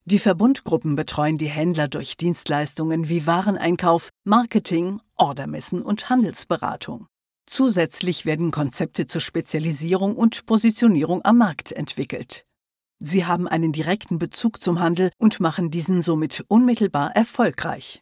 Mit ihrer tiefen, samtigen Charakterstimme gibt die Sprecherin jedem Script einen individuellen Touch.
Sprechprobe: eLearning (Muttersprache):
With her deep, velvety character voice, the speaker gives every script an individual touch.
Writers and listeners alike appreciate the varied realisation of her texts and the outstanding audio quality of the recordings produced in her own studio.
Erklärfilm_Industrie_0.mp3